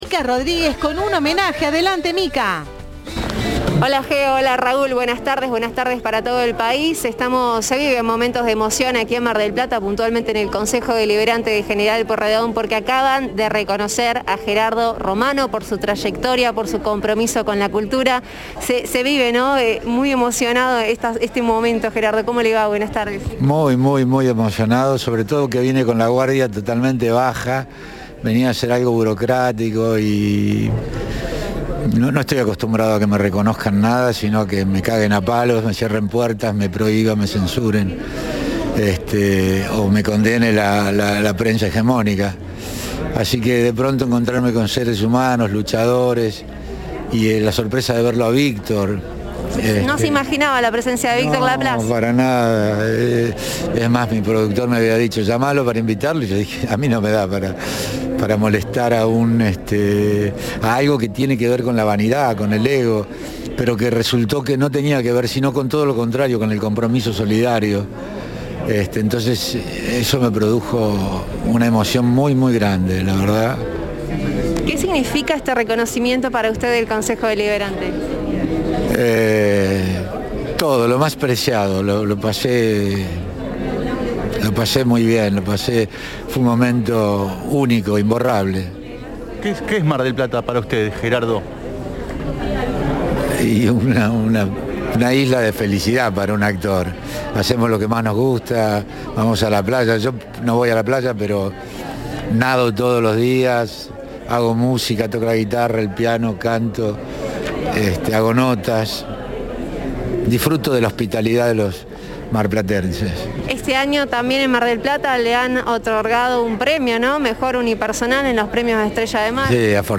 Emocionado, en diálogo con Cadena 3 dijo: “No estoy acostumbrado a que me reconozcan, sino a que me prohíban y condene la prensa hegemónica".
Luego de su reconocimiento, el artista dialogó con Cadena 3 y dijo no estar acostumbrado a los reconocimientos de este tipo, sino a que "me cierren puertas, prohíban, censuren y condene la prensa hegemónica".